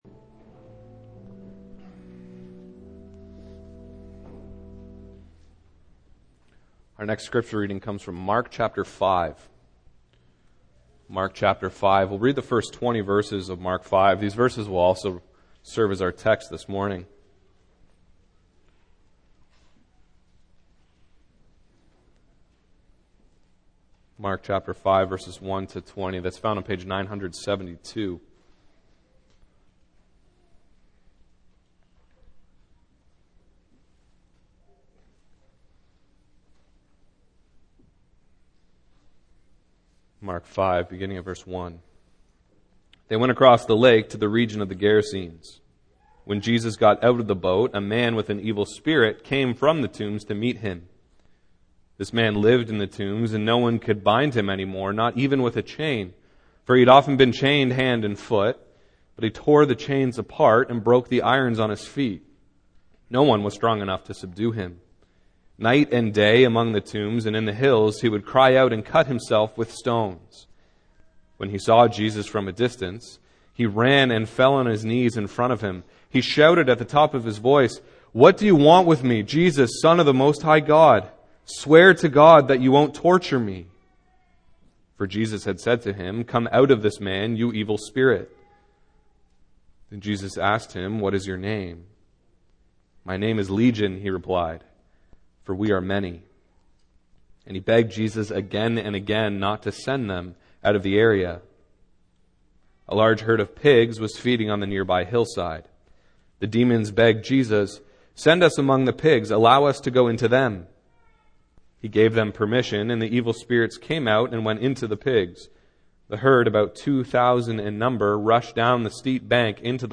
Single Sermons Passage: Mark 5:1-20 Service Type: Morning